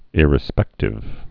(ĭrĭ-spĕktĭv)